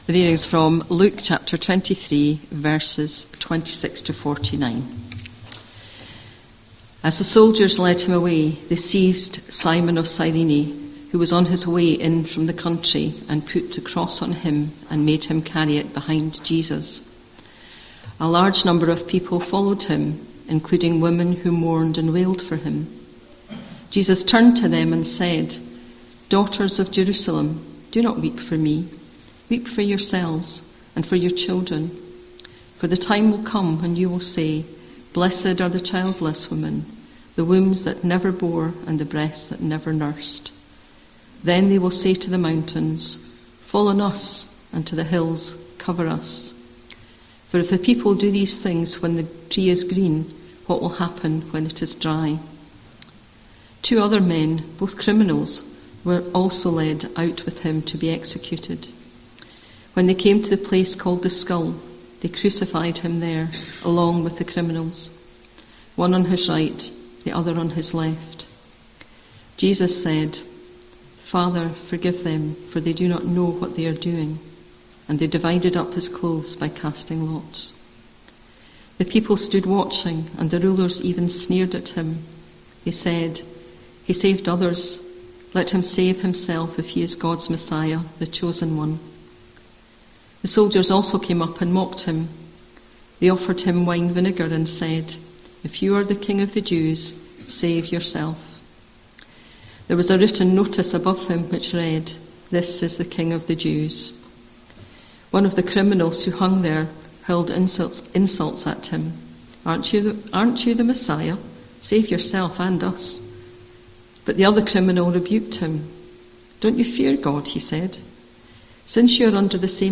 12th October 2025 Sunday Reading and Talk - St Luke's
Apologies for the poor sound quality.